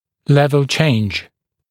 [‘levl ʧeɪnʤ][‘лэвл чейндж]изменение уровня